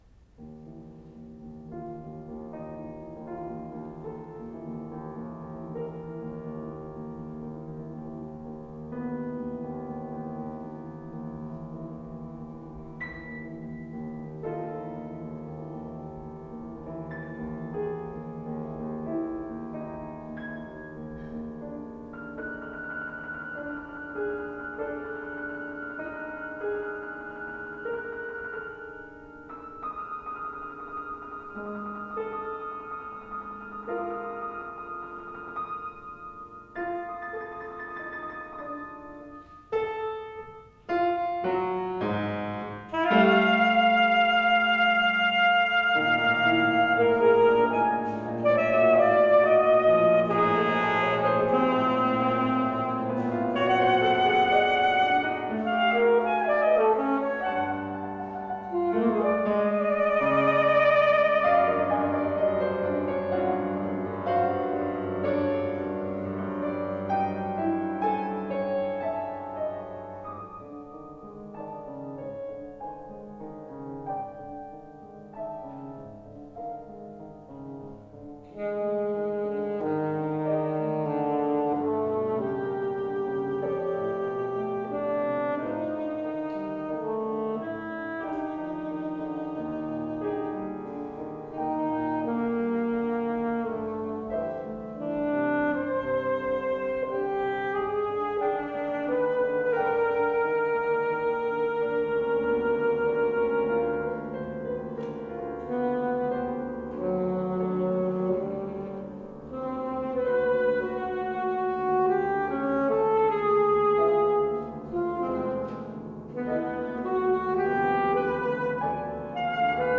This is an early student work, inspired in part by post-Coltrane free jazz, and performed at a university concert in 1982. It also had public performances that same year at Metro-Media, a punk gallery in Vancouver, and was recorded and broadcast on community radio.